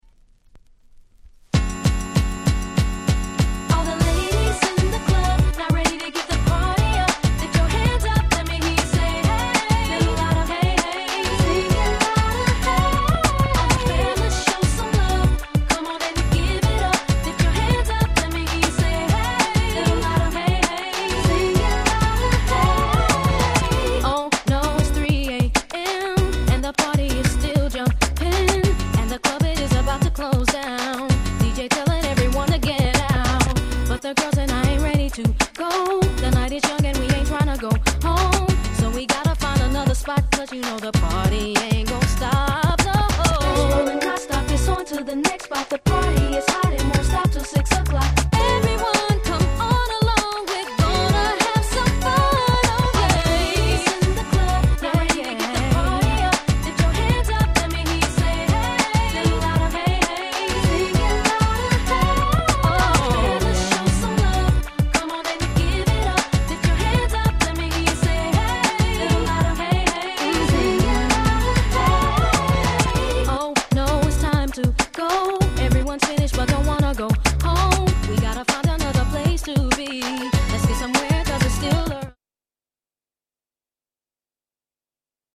底抜けにキャッチーな曲調で、当時Blaque『As If』なんかと一緒にプチヒットしたNice R&B♪
キャッチー系